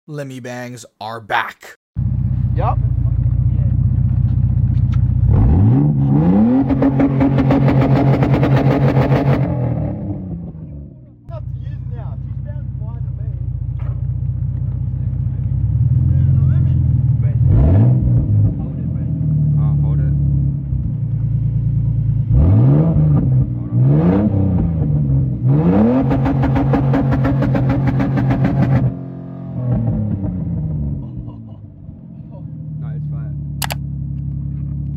BA FALCON LIMMI' BANG! TAG sound effects free download